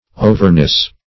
Overnice \O"ver*nice"\, a.